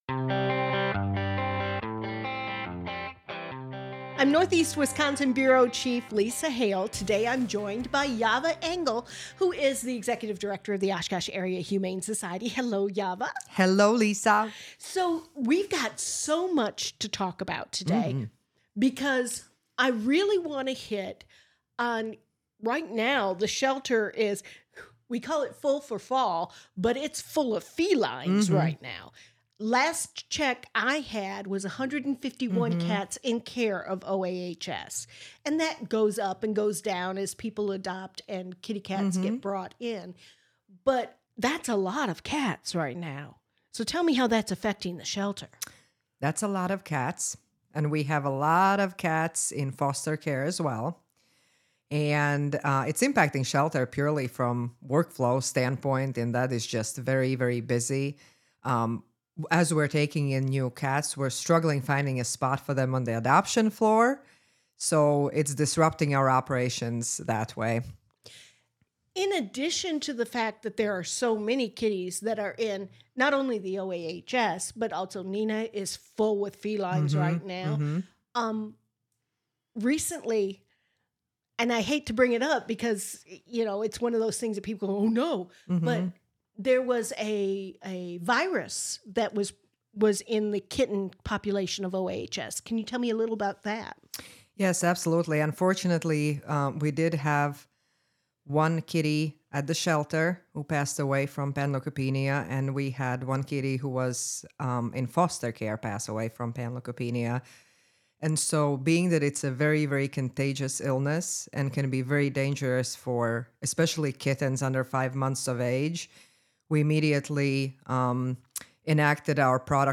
Conversations will range from entertainment to government to community involvement and more!